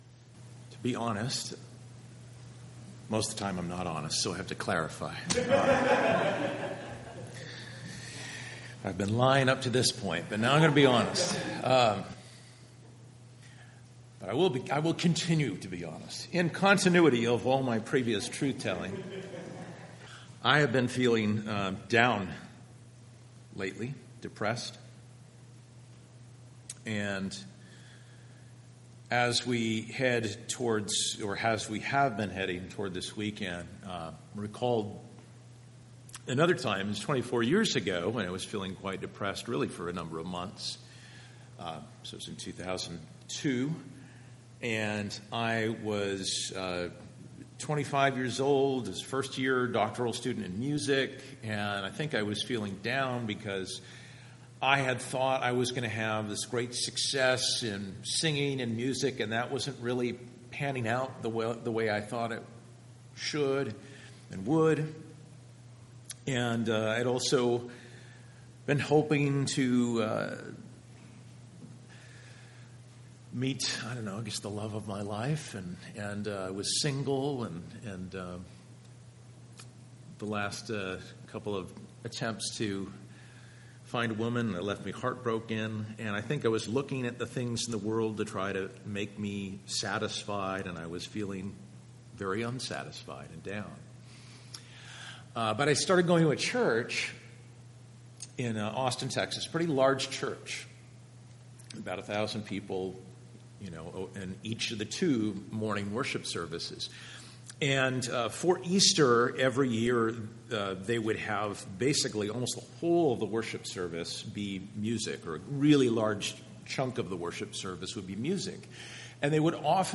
Sermon recordings are in MP3 format and can be listened to or downloaded by clicking on the sermon title next to the date.